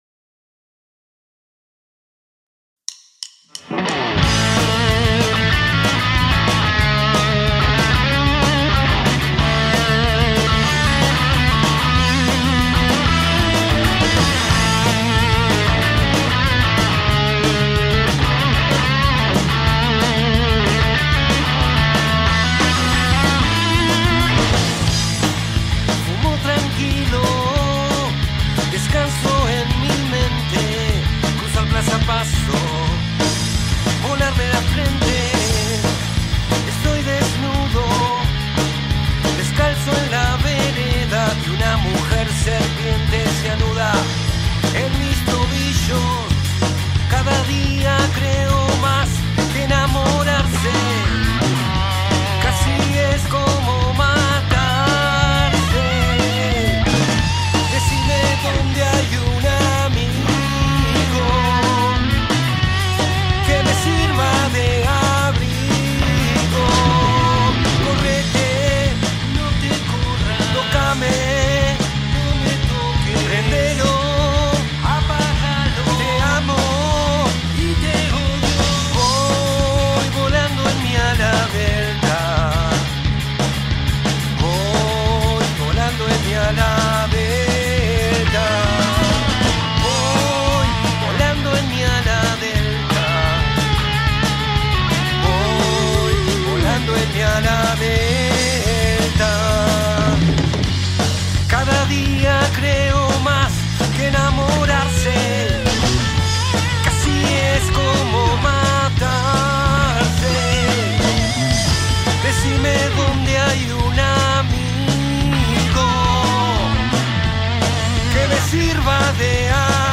batería
primera guitarra
bajo
segunda guitarra